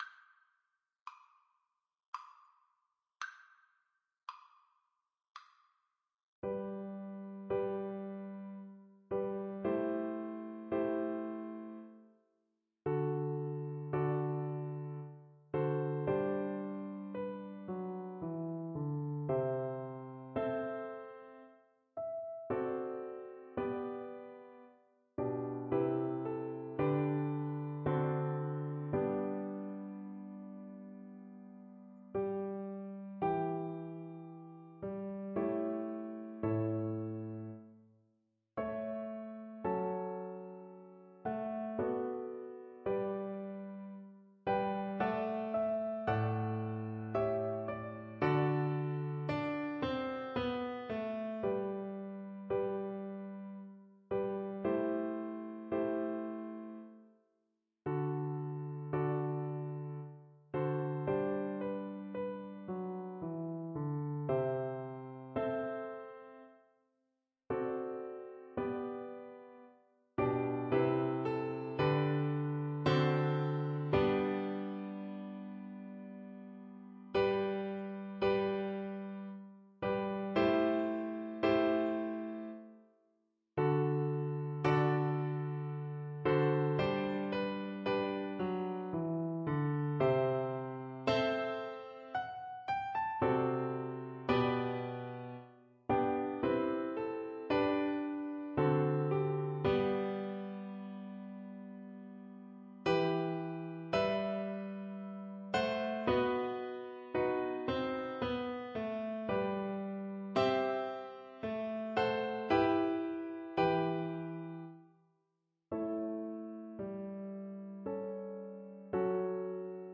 Play (or use space bar on your keyboard) Pause Music Playalong - Piano Accompaniment reset tempo print settings full screen
G major (Sounding Pitch) (View more G major Music for Guitar )
Larghetto = 76
3/4 (View more 3/4 Music)
Guitar  (View more Easy Guitar Music)
Classical (View more Classical Guitar Music)